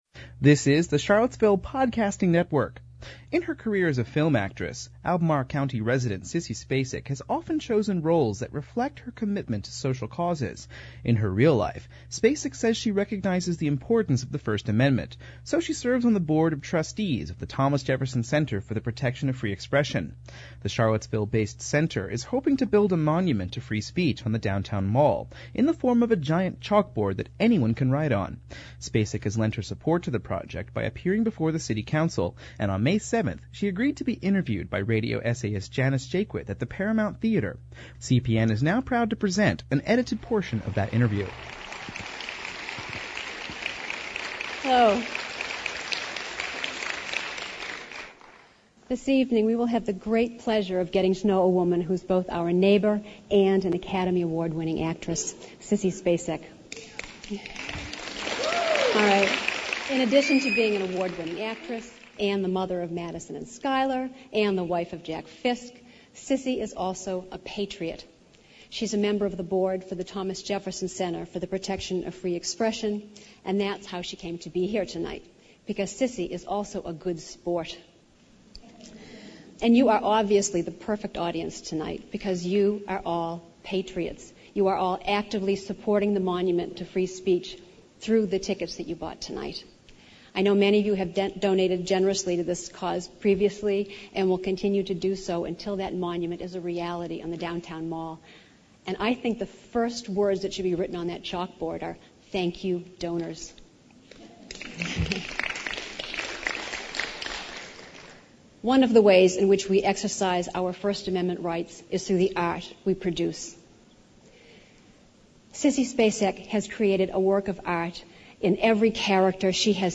This 27 minute documentary pilot is an experiment in raw reporting from the February 17 meeting of the Nuclear Regulatory Commission. The idea for the show is to send one producer or reporter into a public hearing where a controversial issue is up for discussion. The producer talks with the people who attend, and these interviews are not reduced to soundbites, making for a much longer story, but one that better represents the complexities of the issues at hand.